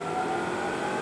Accelerate1.wav